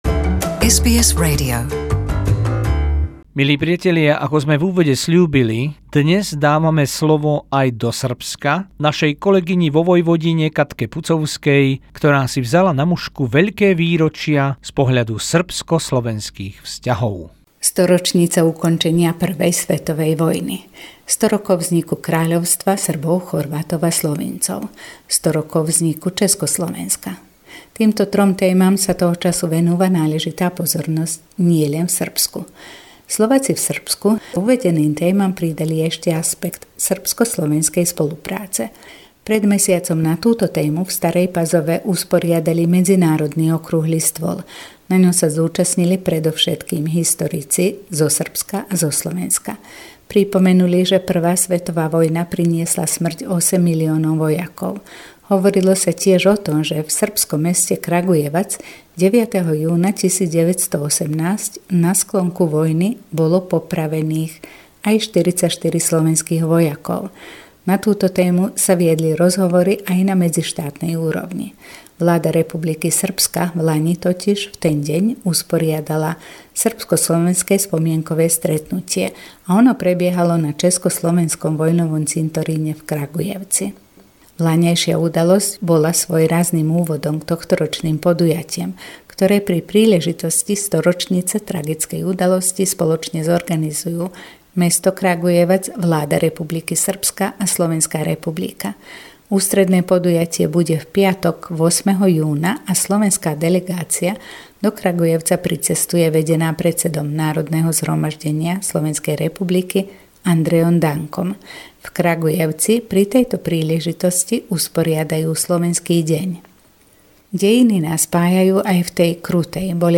Zvuková pohľadnica našej kolegyne v Srbsku o veľkých výročiach vo vzájomných vzťahoch Srbov a Slovákov